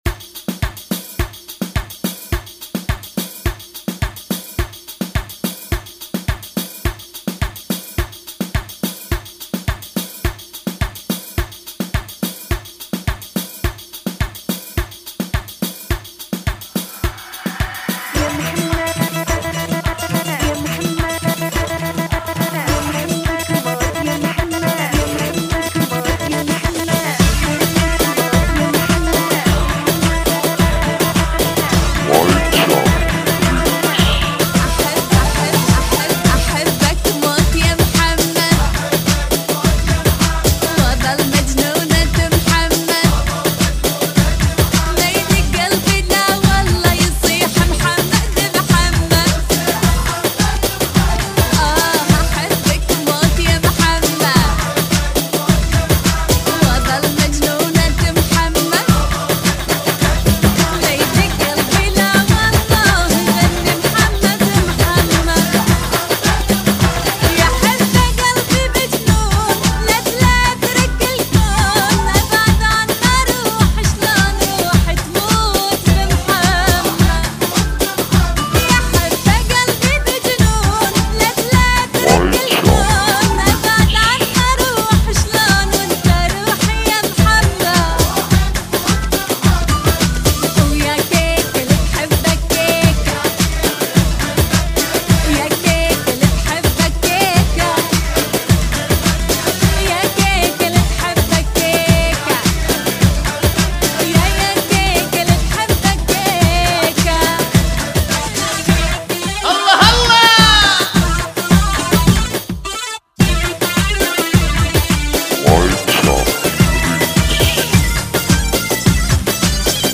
فنكي مكس